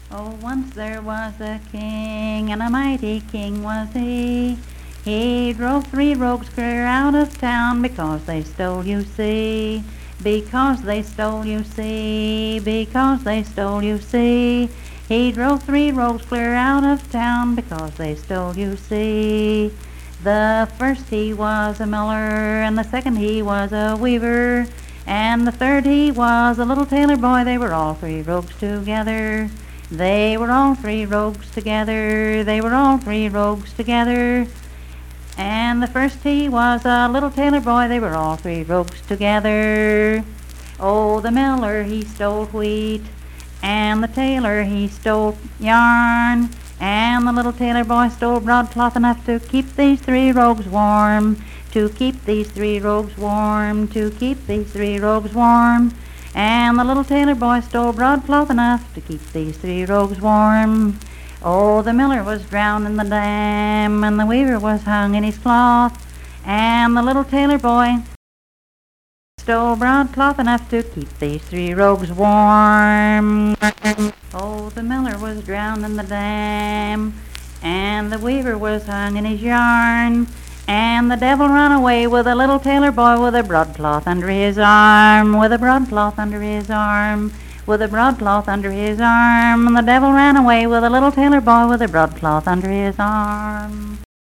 Unaccompanied vocal music
Verse-refrain 4d(4w/R).
Performed in Coalfax, Marion County, WV.
Voice (sung)